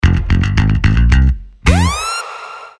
• Качество: 320, Stereo
гитара
звонкие
Перебор на бас-гитаре (или около того)